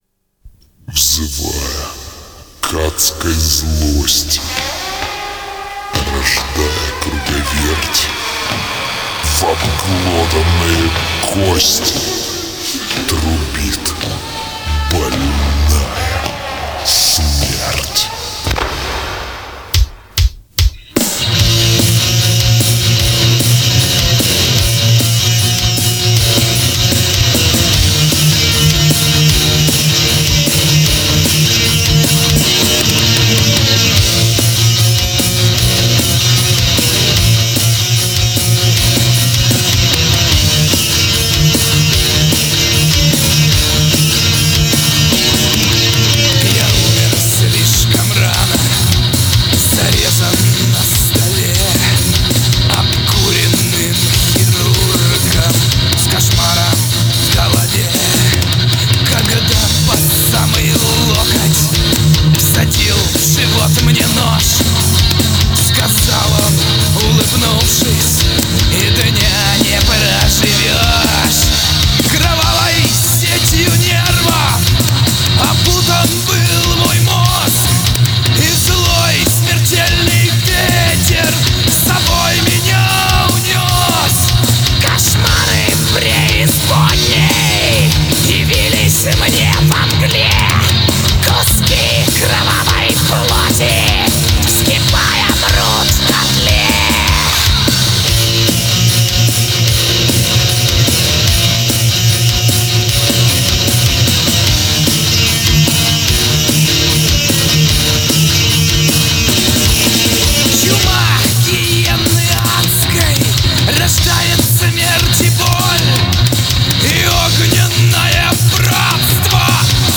Thrash Metal
гитара
вокал
бас
барабаны